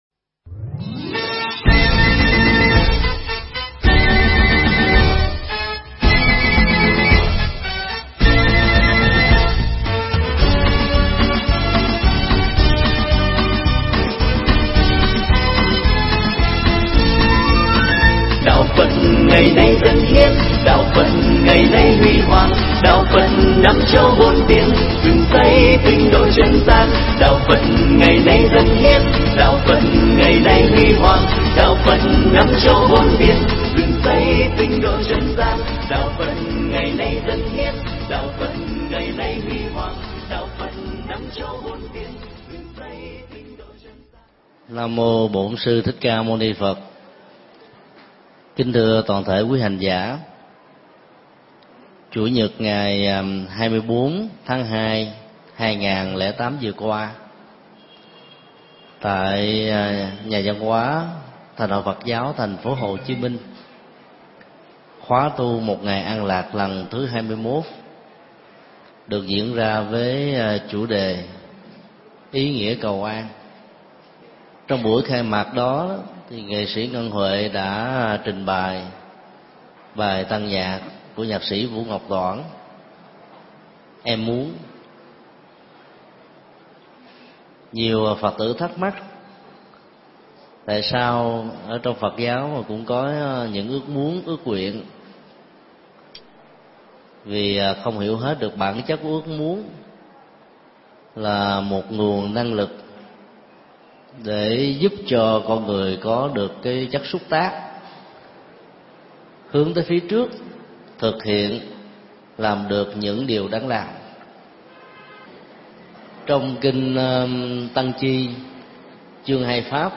Nghe mp3 thuyết pháp Những Điều Ước Nguyện được thầy Thích Nhật Từ giảng tại Chùa Ấn Quang, ngày 02 tháng 03 năm 2008.